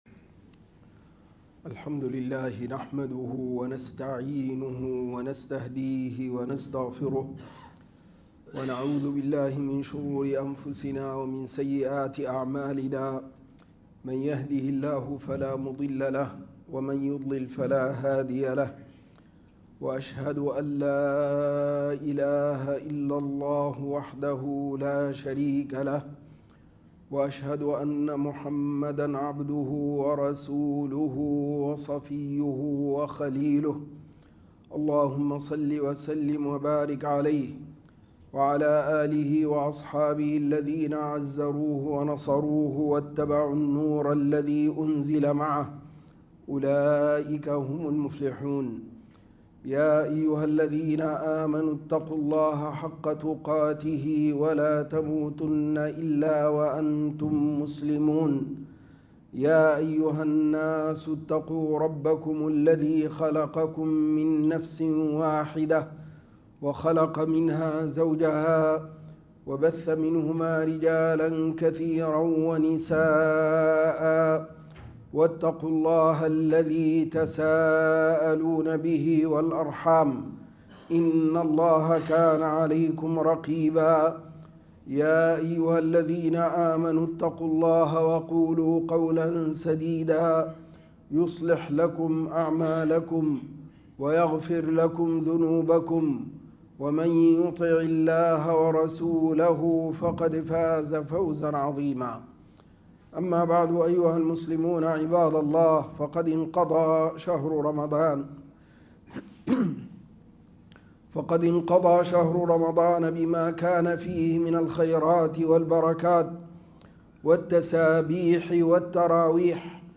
حتى يأتيك اليقين _ خطبة الجمعة 4-4-2025